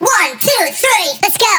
VR_vox_hit_123letsgo.wav